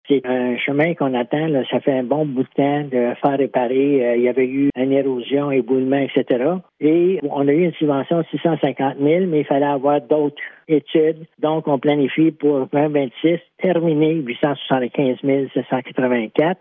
Le maire, Robert Bergeron, a expliqué que la Municipalité allait investir plus de 875 000 $ pour réparer le chemin :